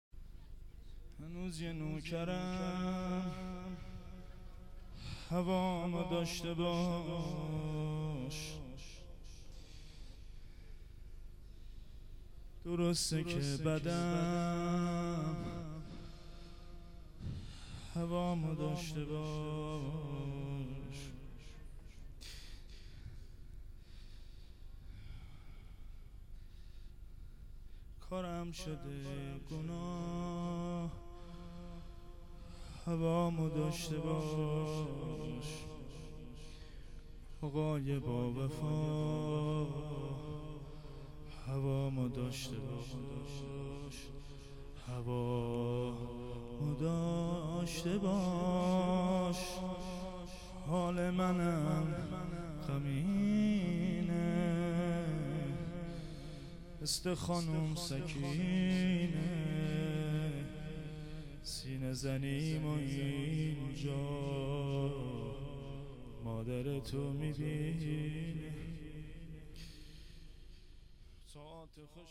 شعر خوانی.wma
شعر-خوانی.wma